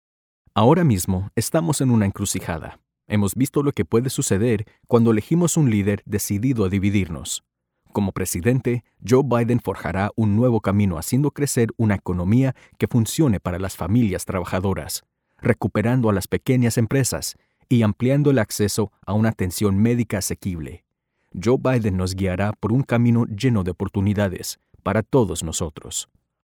Spanish-speaking male voice actor
Spanish-Speaking Men, Political